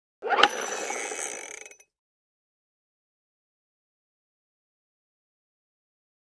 Звуки детонатора
Поршень ручного детонатора для взрывных работ